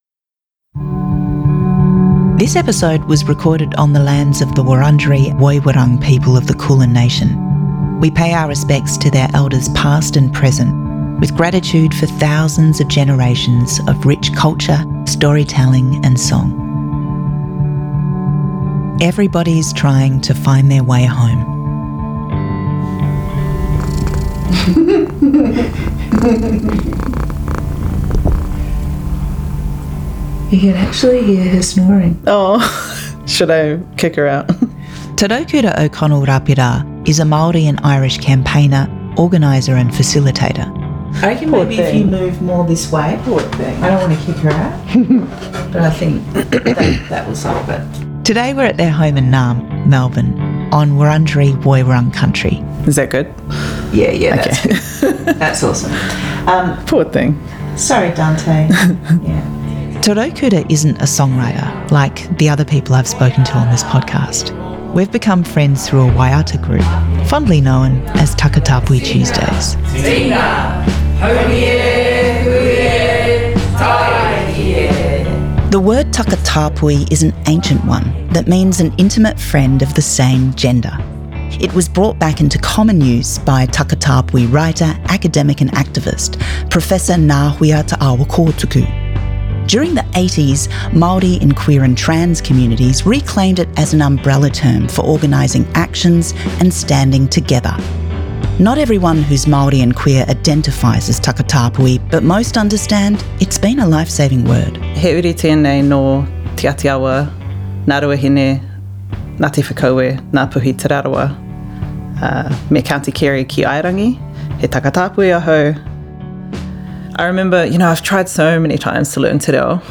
Be a guest on this podcast Language: en Genres: Music , Music Interviews , Society & Culture Contact email: Get it Feed URL: Get it iTunes ID: Get it Get all podcast data Listen Now...